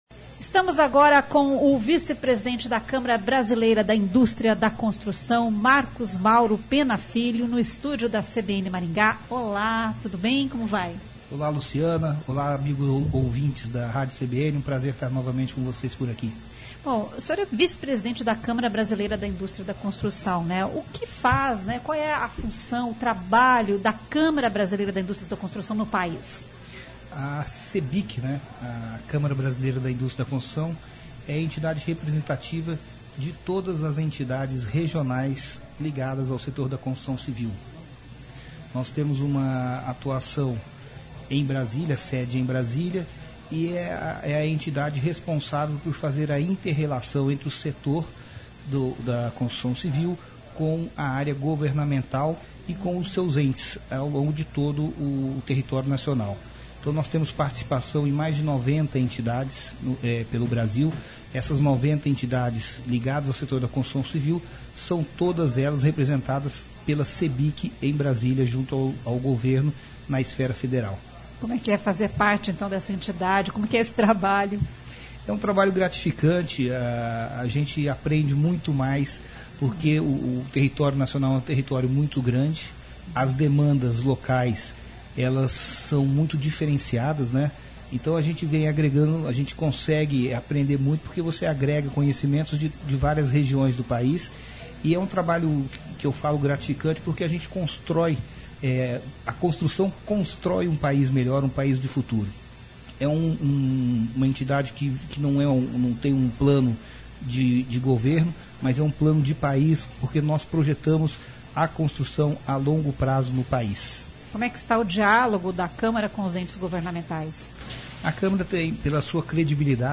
Expoingá 2023